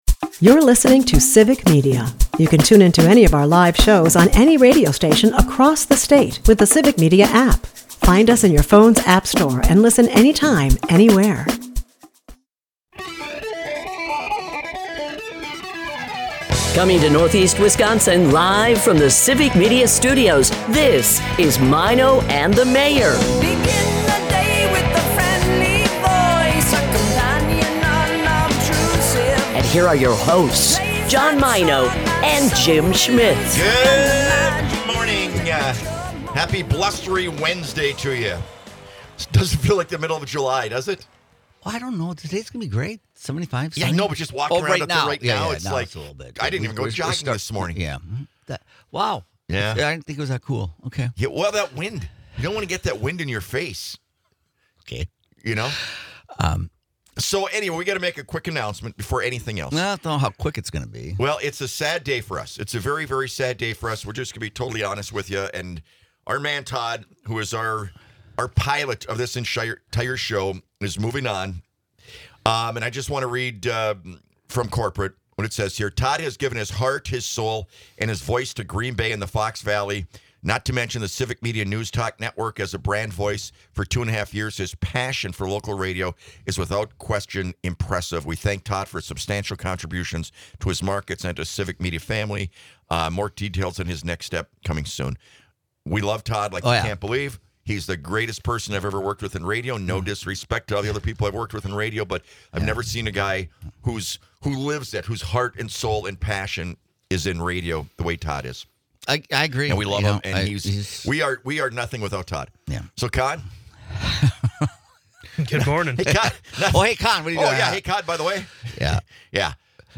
The duo also dives into a lively discussion about music legends, nostalgic concerts, and the wild world of 70s rock, touching on artists like Cheap Trick and Heart. Amidst laughs and memories, they contemplate fashion, dimples, and the cultural footprint of iconic musicians and actors.